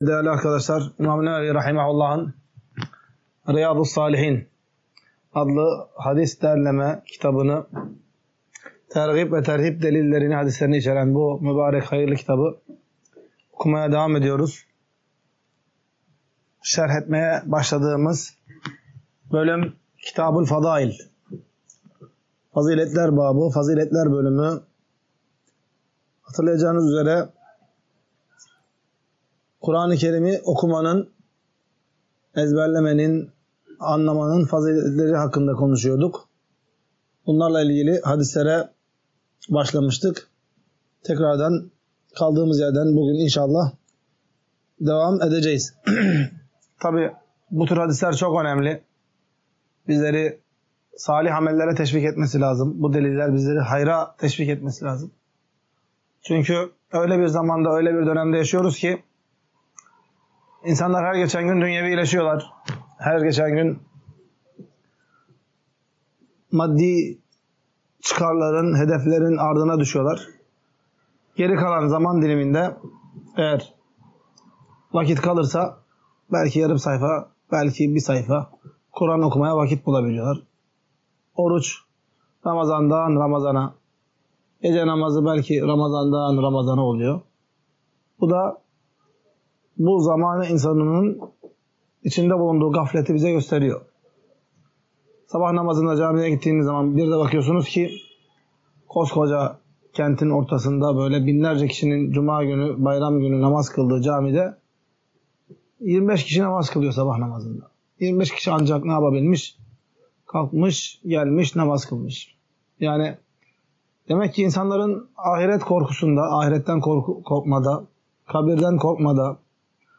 Ders - 3.